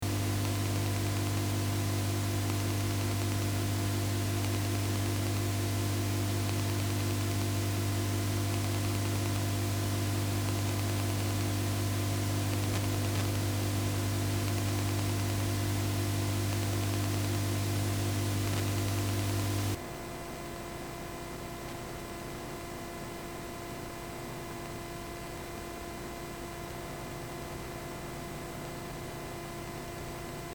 Rhythmische Nebengeräusche aus dem Amp?
Hallo, Erstmal zu meinem Setup; Ich spiele meine Amps zuhause über ein Suhr Reactive Load in ein Mooer Radar.
Nun höre ich dauerhaft ein rhythmisches Nebengeräusch.
Was könnte ich noch tun? Habe mal ein mp3 von dem Geräusch angehängt, zuerst mit eingestecktem Kabel (ohne Gitarre), ab dem letzten drittel ohne Gitarre.